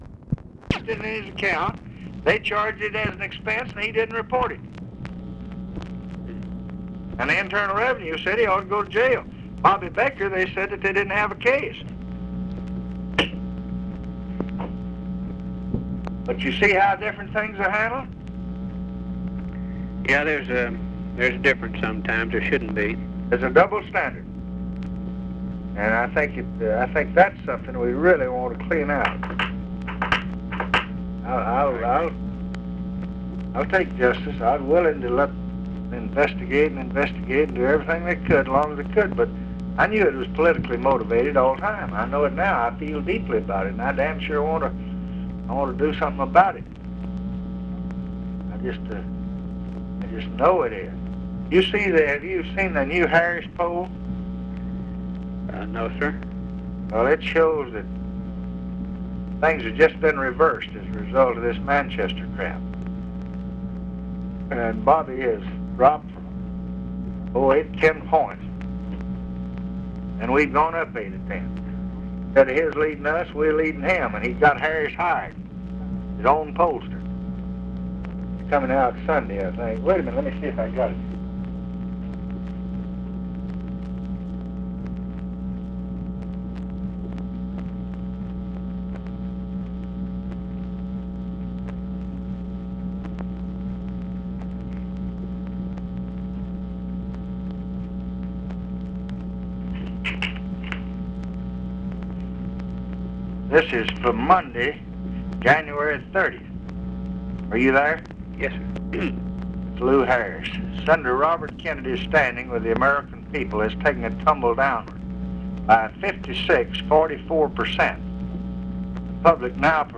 Conversation with RAMSEY CLARK, January 26, 1967
Secret White House Tapes